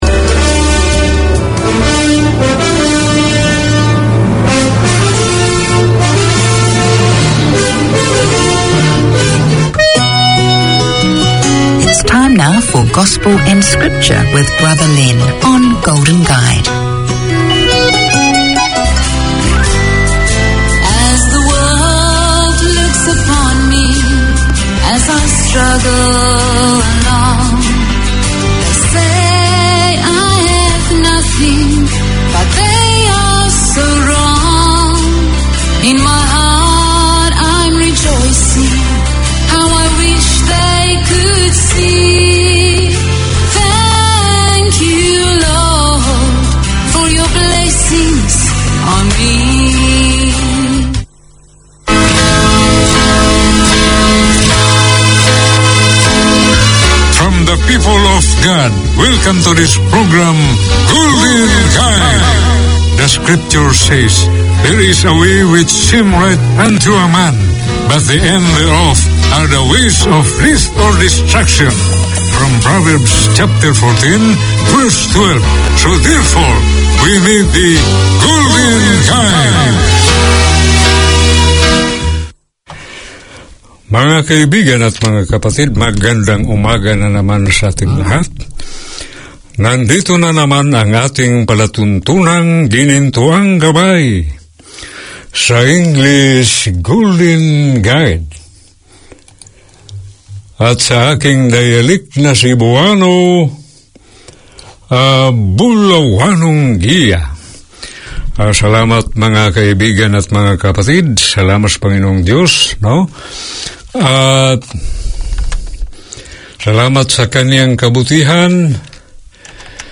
Tune in for scripture and gospel music, as well as occasional guest speakers who share their passion and faith.